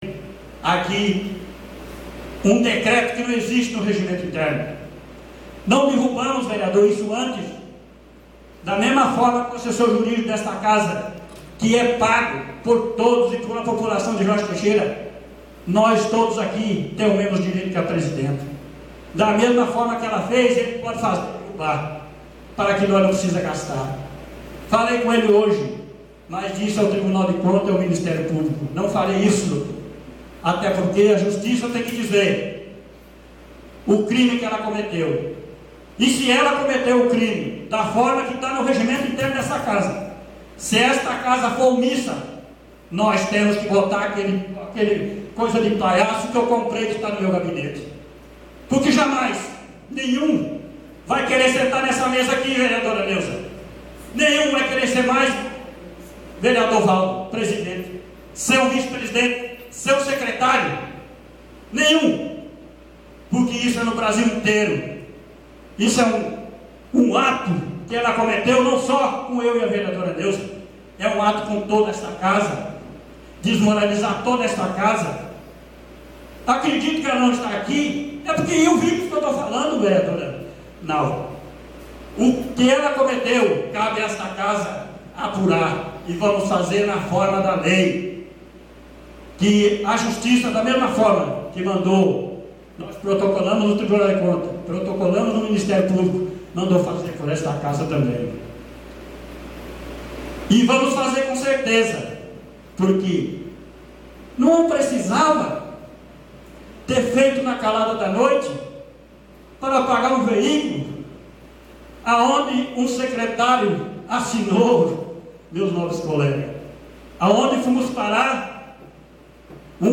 RESENHA DA CÂMARA – Durante a tarde desta Segunda-feira (15) aconteceu a Sessão na Câmara Municipal de Governador Jorge Teixeira que marcou o inicio do ano legislativo de 2016. No plenário Euclides Severo da silva, oito dos nove vereadores do município estiveram presentes, e todos eles, com exceção da Presidenta da Casa, que se ausentou após fazer a abertura dos trabalhos, fizeram uso da Tribuna da Casa de Leis.